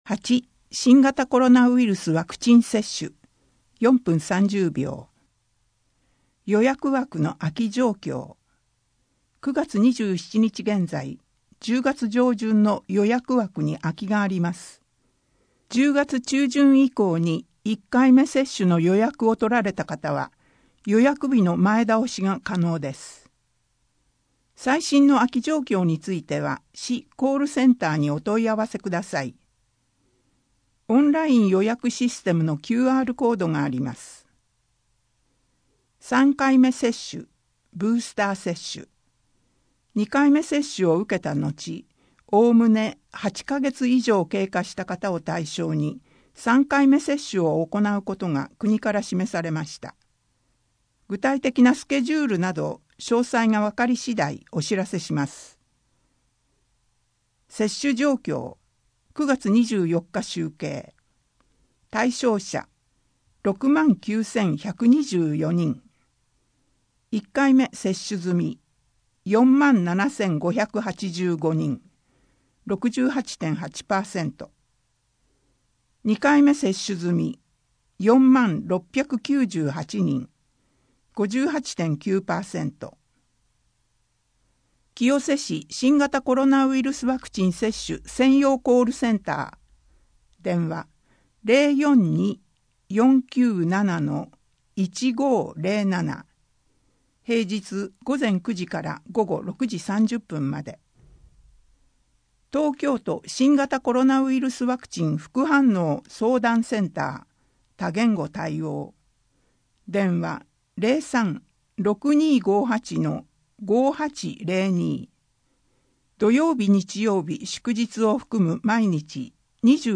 清瀬鉄道物語」 図書館からのお知らせ 子ども図書館スタンプラリー 野塩図書館からのお知らせ 予約資料の受け渡しについて 野塩図書館からのお知らせ 野塩図書館ブックポストのご利用について 10・11月の子育て関連事業 10月の休日診療 24時間電話で聞ける医療機関案内 平日小児準夜間診療 令和3年10月1日号8面PDF （PDF 589.2KB） 声の広報 声の広報は清瀬市公共刊行物音訳機関が制作しています。